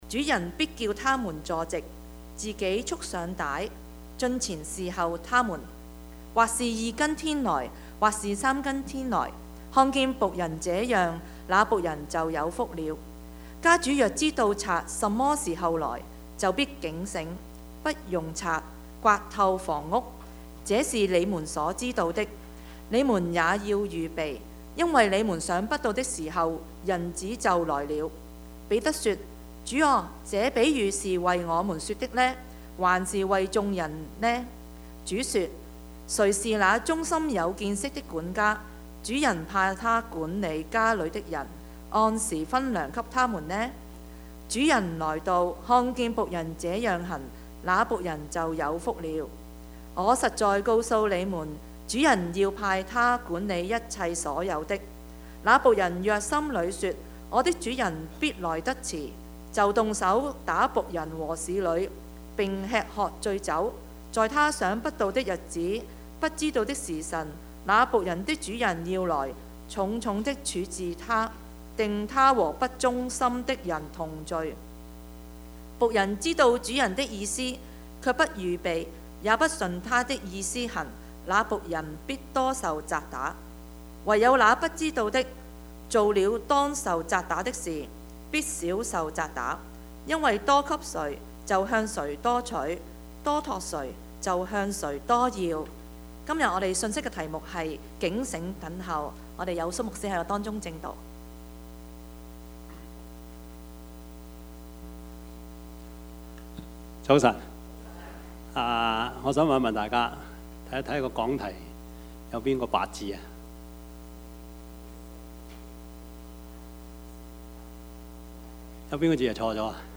Service Type: 主日崇拜
Topics: 主日證道 « 人無遠慮, 必有近憂 化時為機 »